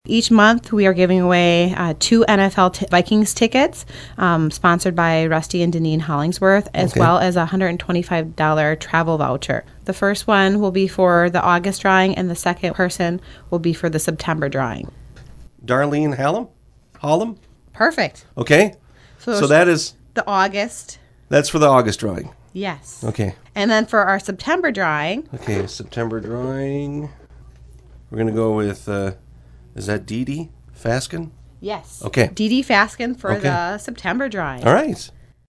drew the winners this (Wed.) morning on the KGFX morning show.